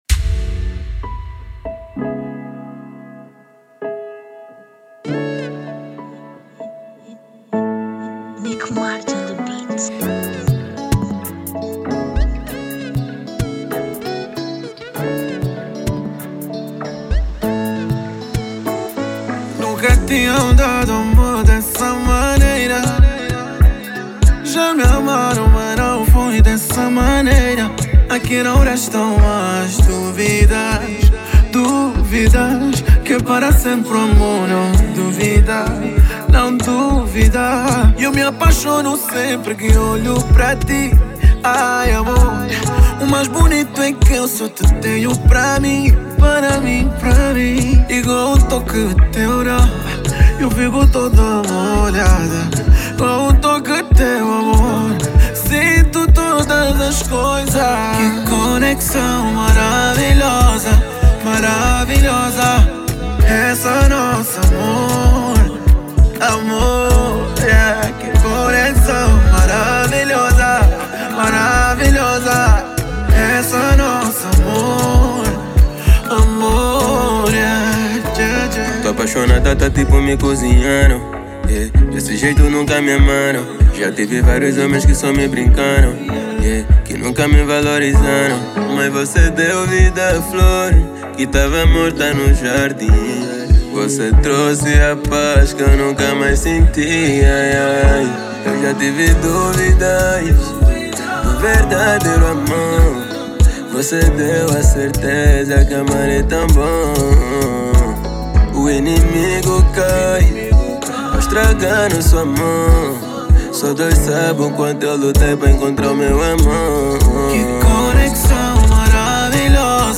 Genero: Kizomba